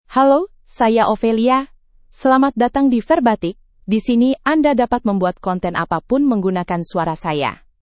OpheliaFemale Indonesian AI voice
Ophelia is a female AI voice for Indonesian (Indonesia).
Voice sample
Female
Ophelia delivers clear pronunciation with authentic Indonesia Indonesian intonation, making your content sound professionally produced.